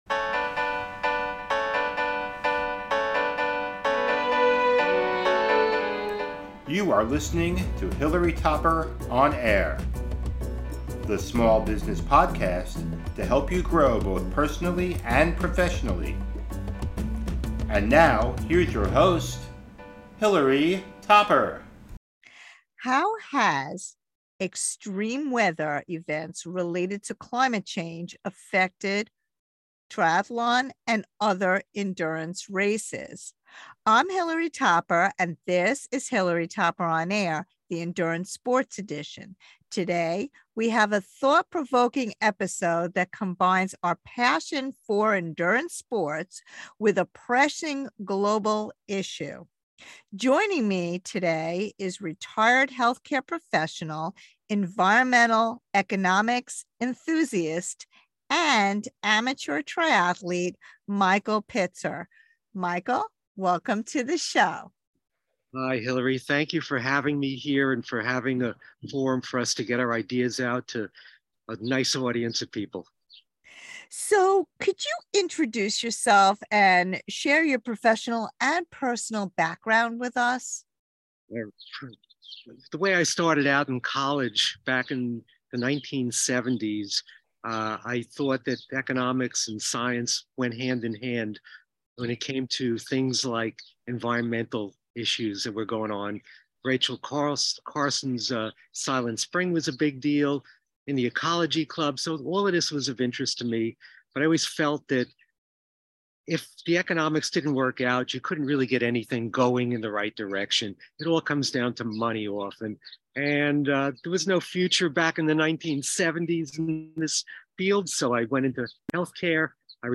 About the Interview Extreme weather events, exacerbated by climate change, have become more frequent and intense.